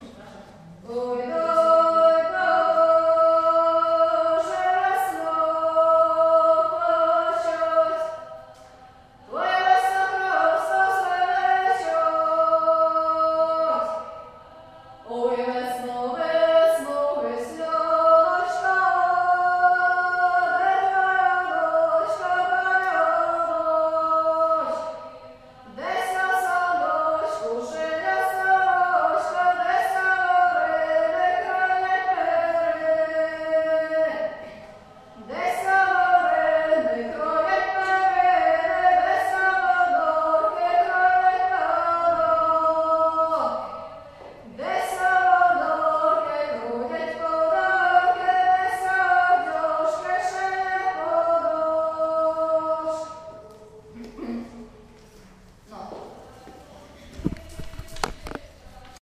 Ukrainian folk singing workshop